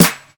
Crisp Snare One Shot F Key 336.wav
Royality free snare tuned to the F note. Loudest frequency: 5370Hz
crisp-snare-one-shot-f-key-336-zZ9.ogg